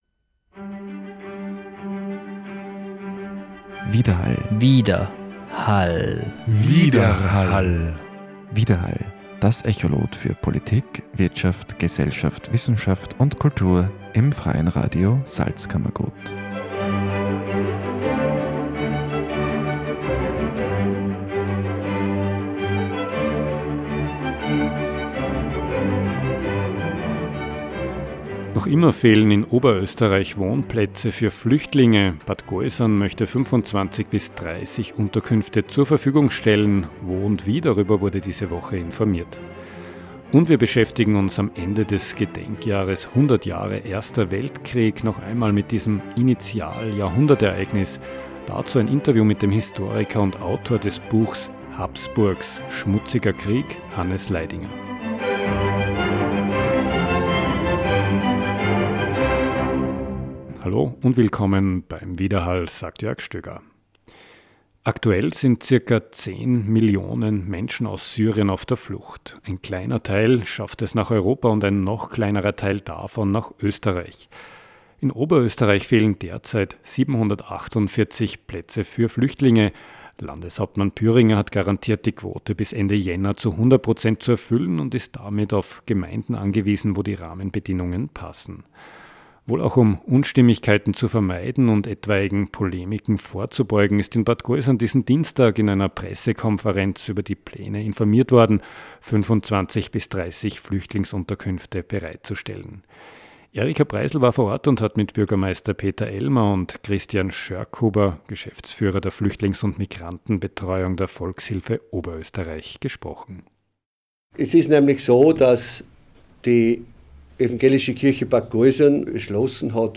Diese Woche wurde in einer Pressekonferenz über die Details informiert. Im Beitrag kommen Bürgermeister Peter Ellmer